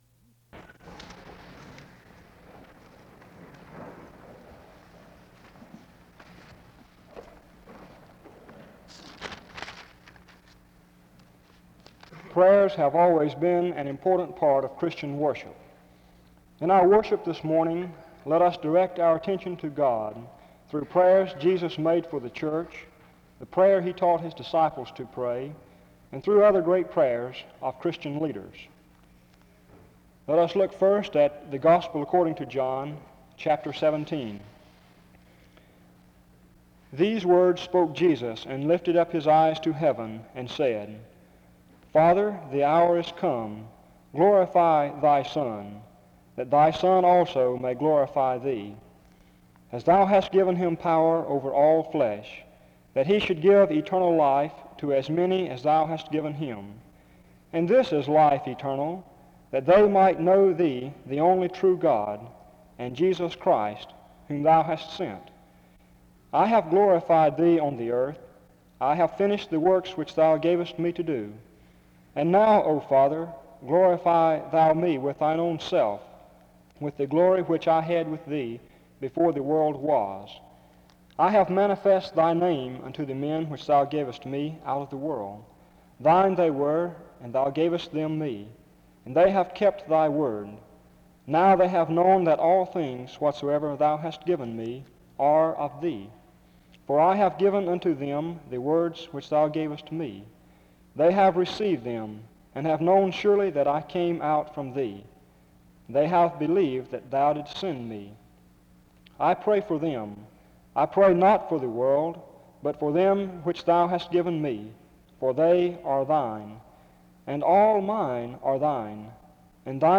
File Set | SEBTS_Chapel_Let_Us_Pray_1970-04-28.wav | ID: 60cdaff4-be0d-4360-81d1-ce8ead5c887b | Hyrax